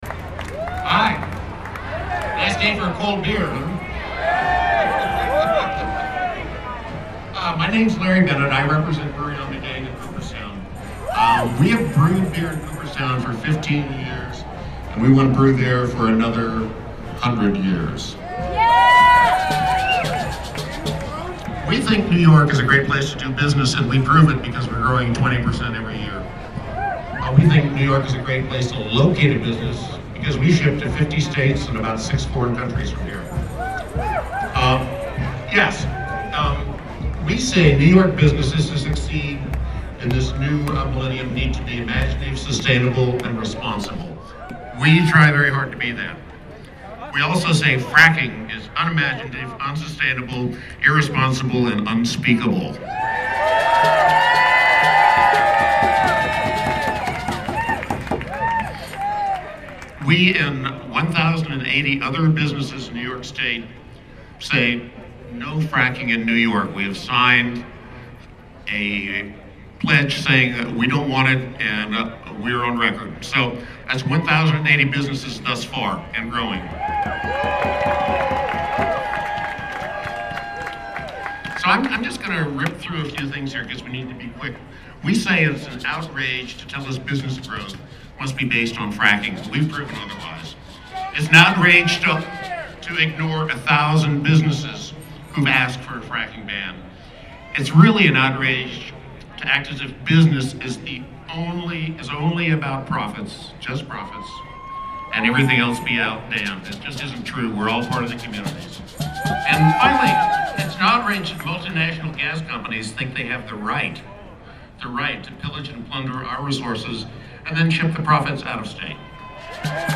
East Capitol Lawn